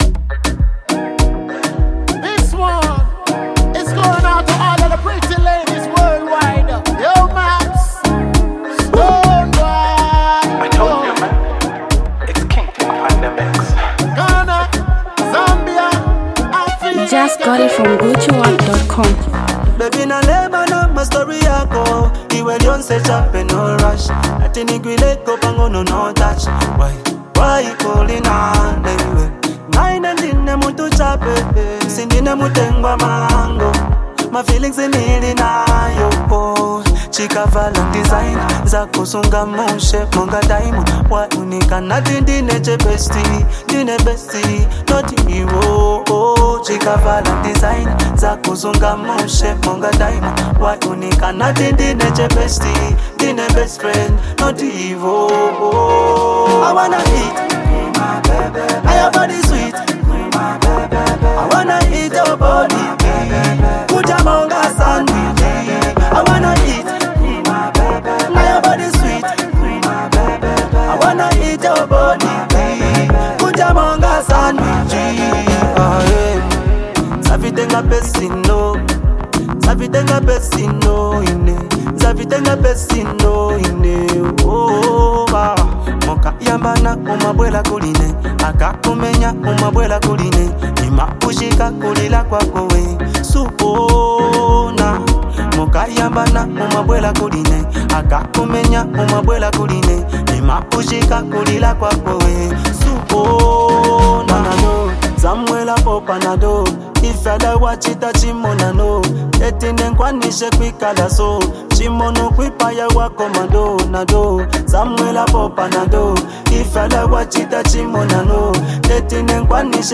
Zambian Mp3 Music
summerly music joint